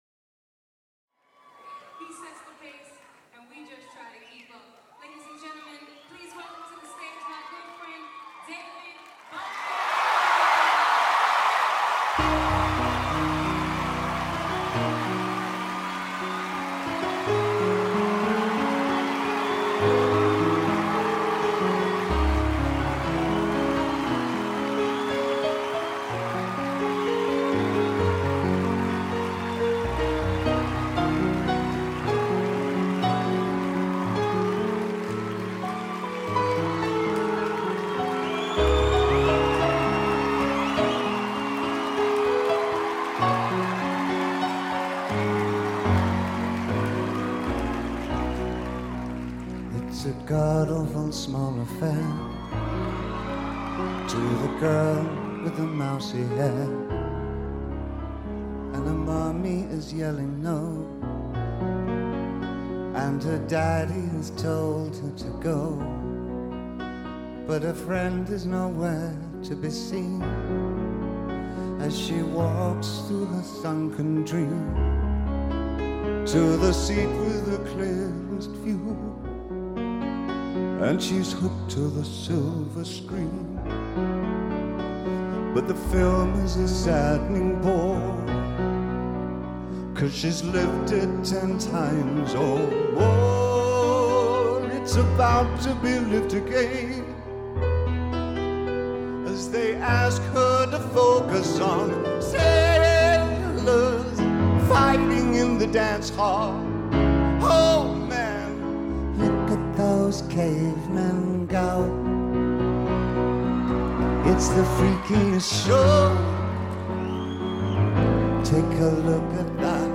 (live)